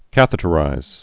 (kăthĭ-tə-rīz)